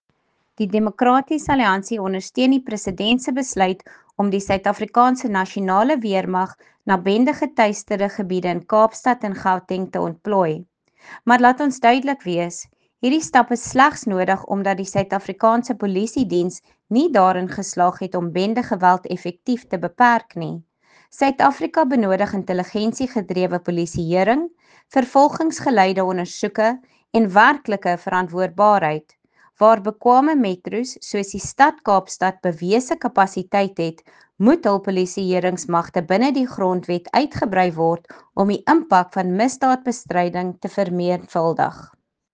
Issued by Lisa Schickerling MP – DA Spokesperson on Police
Afrikaans soundbites by Lisa Schickerling MP.